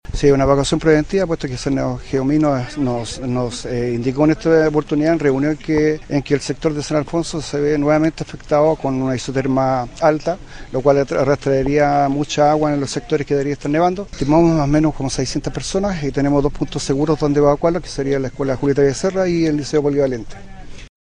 alcalde-san-jose.mp3